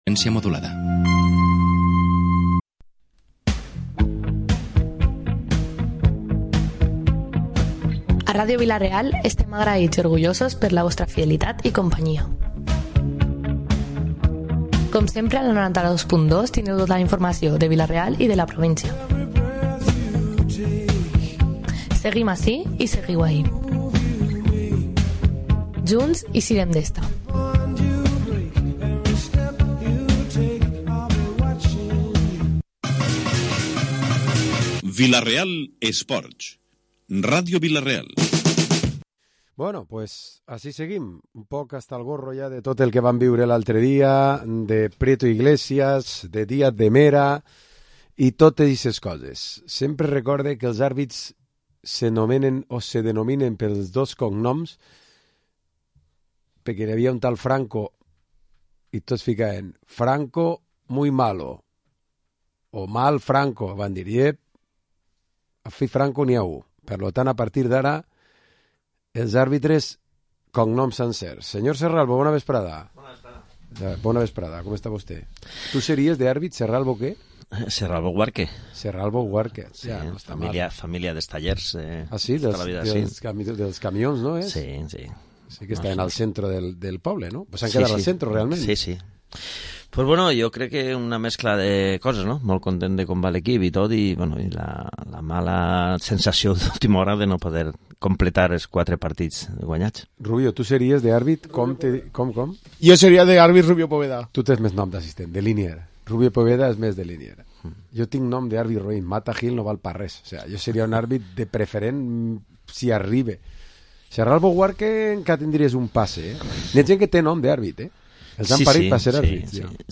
Programa esports tertúlia dilluns 4 de Desembre